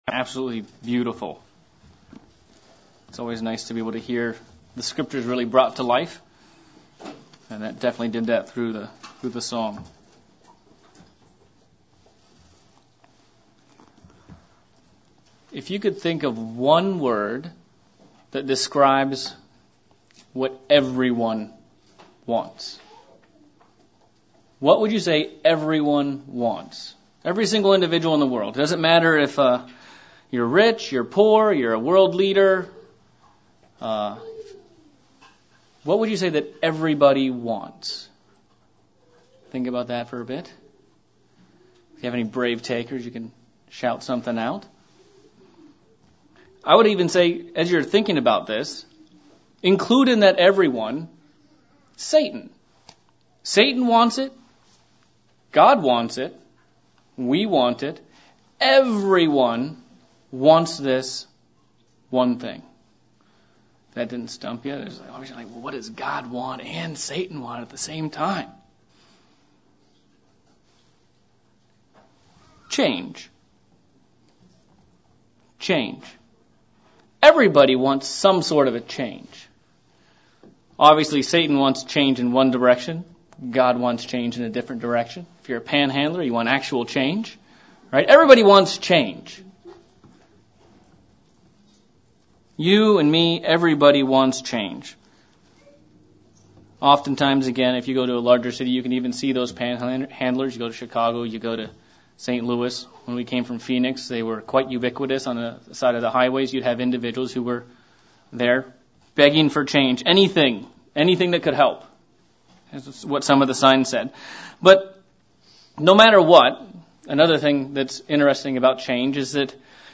Last Day of UB AM sermon. How can we look at the Days of Unleavned Bread and take the changes that God has for us to make into our hearts and lives?